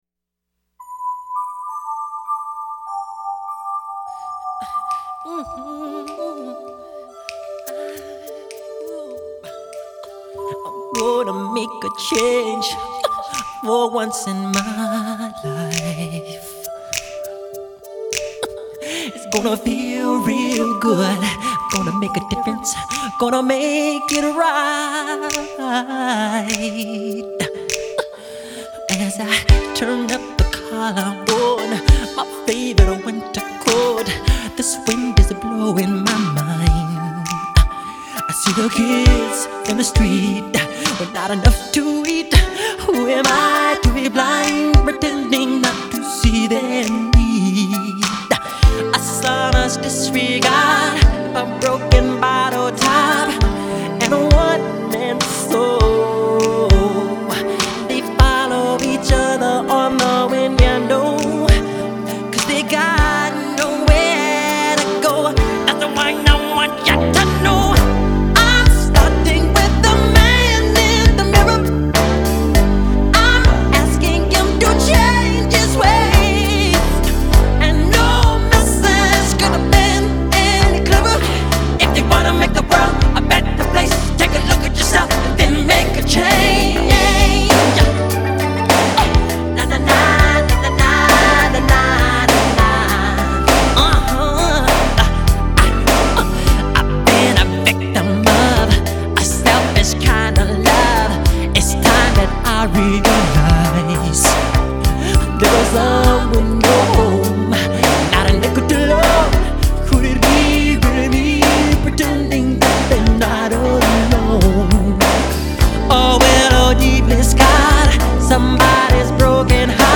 Жанр: Pop-Rock, Soul Pop, downtempo, Funk